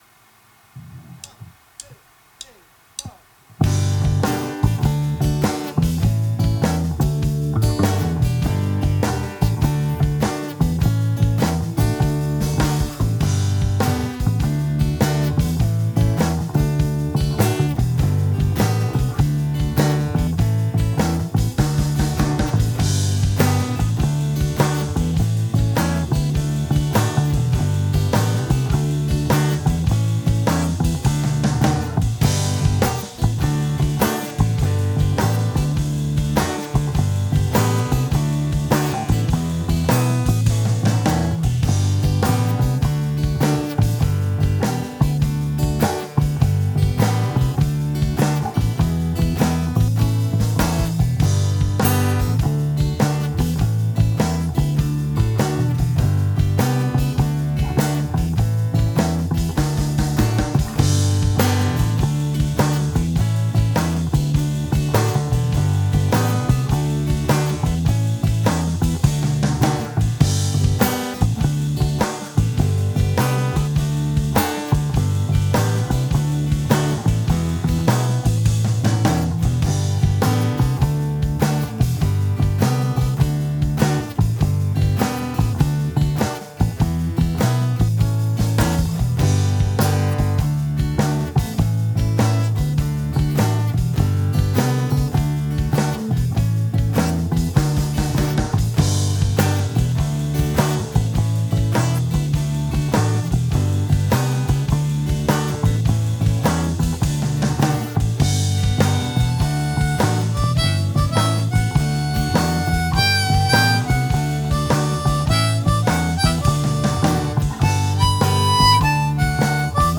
Versão instrumental: